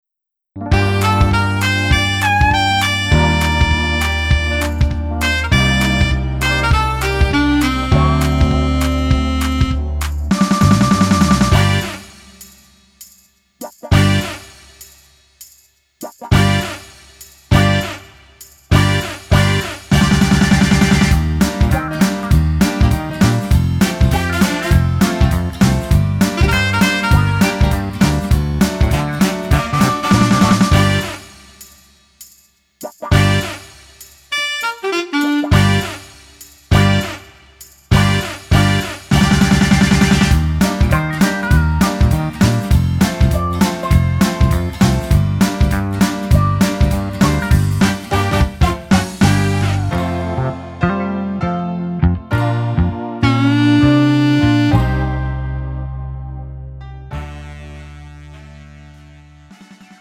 음정 원키 3:08
장르 가요 구분 Pro MR